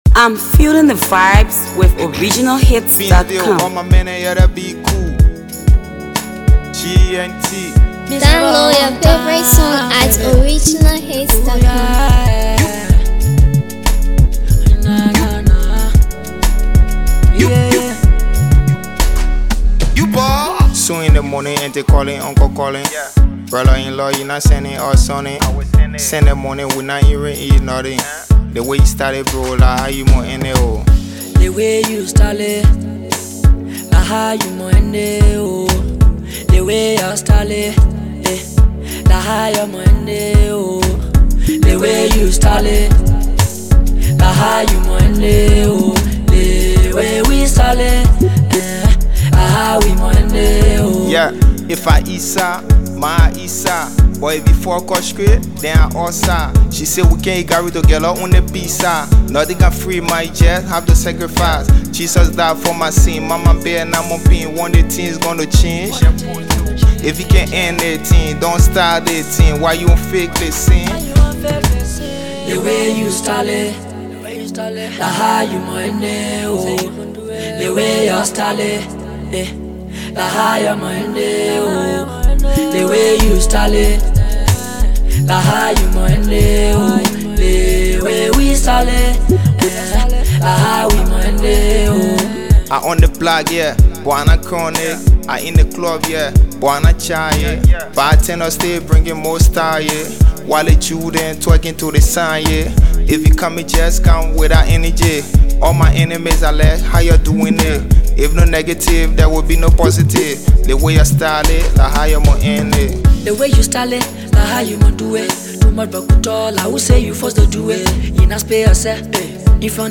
Talented Liberian artist